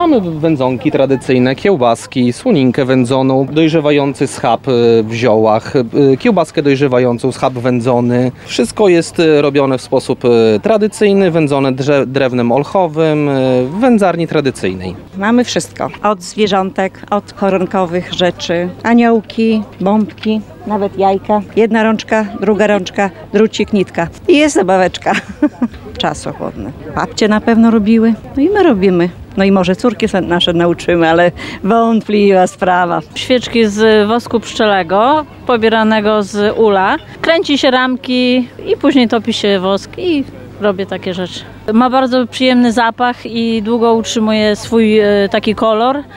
Dożynki Parafialne odbywają się w niedzielę (25.08) w pokamedulskim klasztorze nad Wigrami.
sprzedawcy.mp3